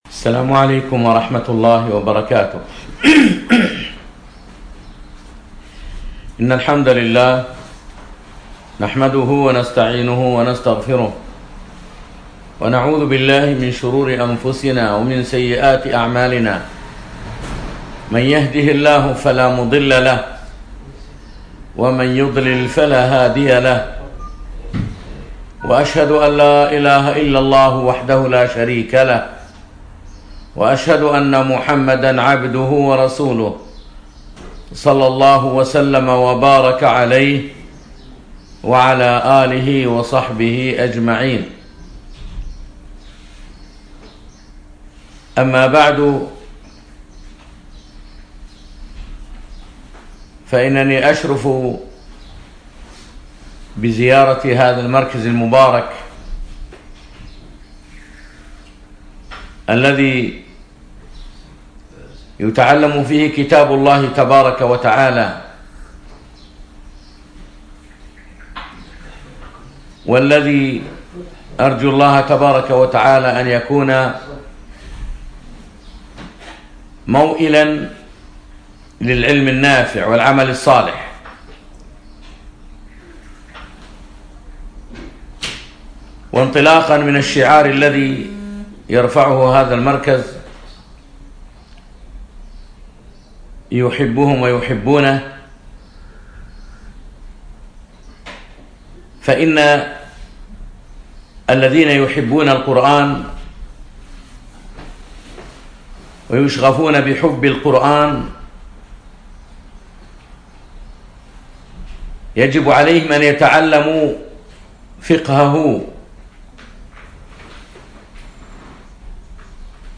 الأحد 5 رجب 1438 الموافق 2 4 2017 أترجة الفحيحيل نسائي صباحي